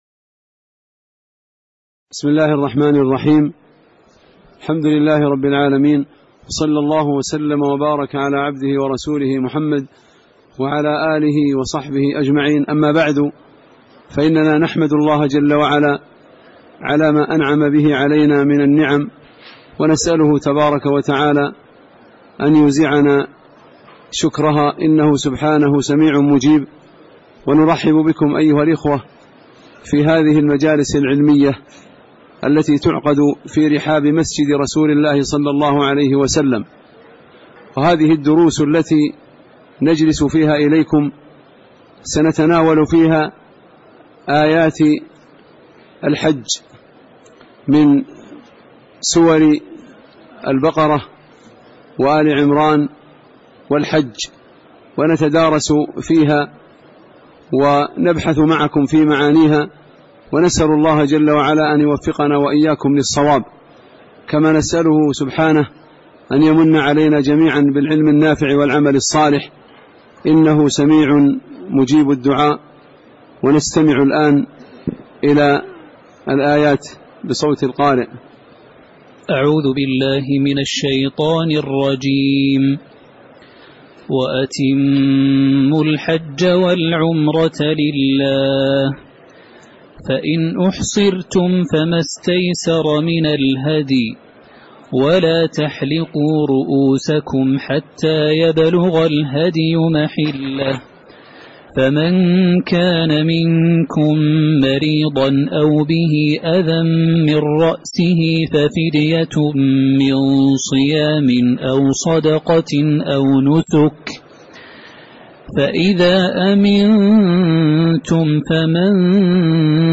تاريخ النشر ٢١ ذو القعدة ١٤٣٨ هـ المكان: المسجد النبوي الشيخ